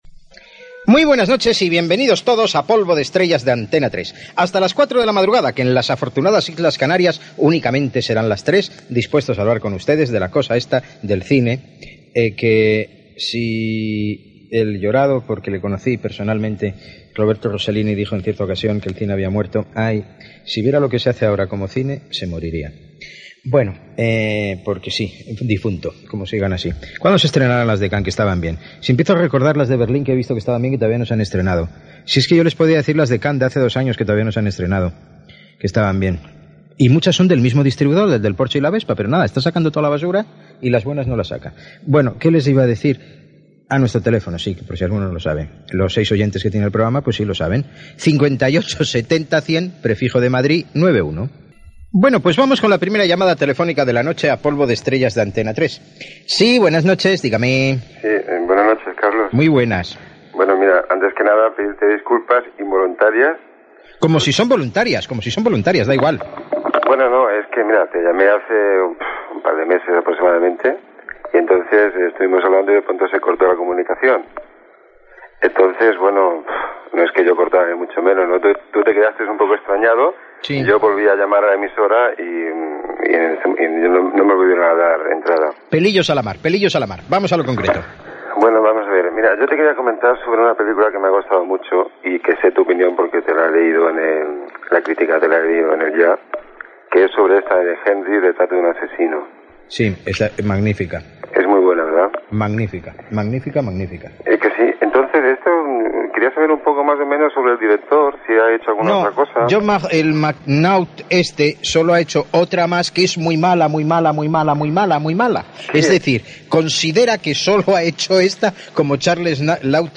Presentació, telèfon, trucada telefònica